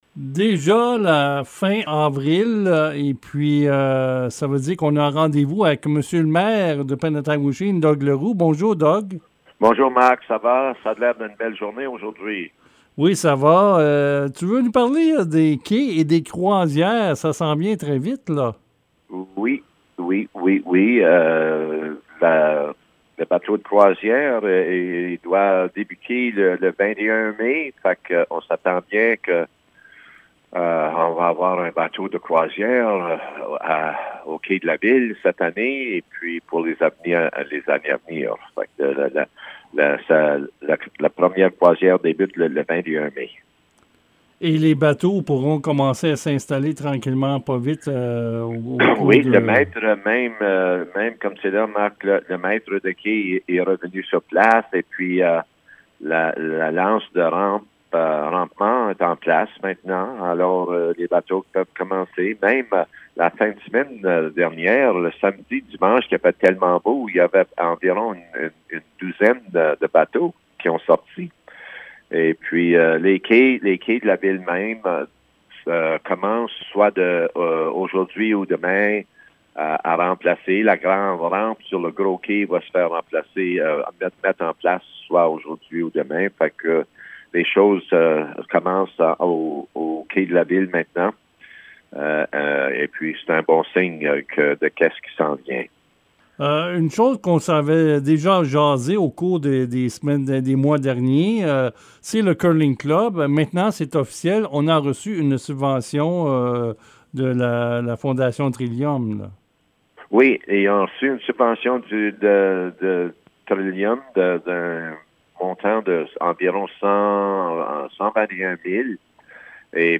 Nous voici à la fin avril et comme à toutes les fins de mois, le maire de Penetanguishene Doug Leroux vient s'entretenir avec les auditeurs de CFRH 88,1 sur les choses à surveiller au niveau de la municipalité.